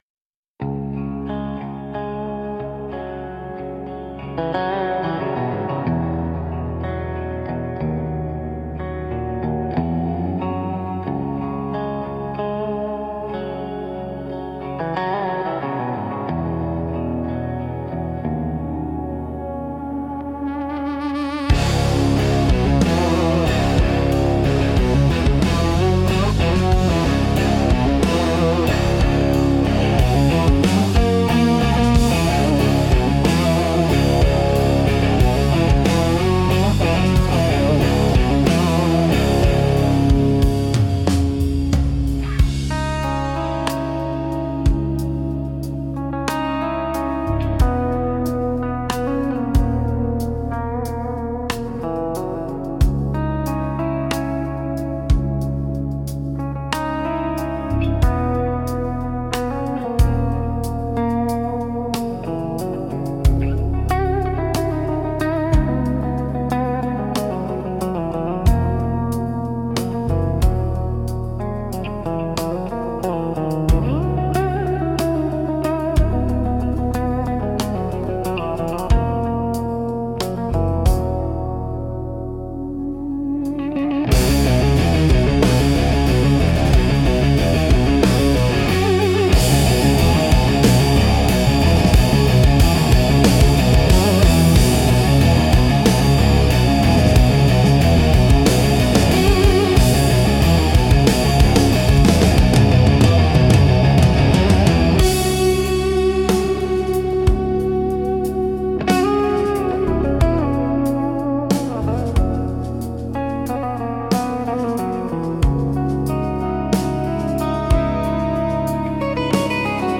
Instrumental - The Glow of Departure 4.44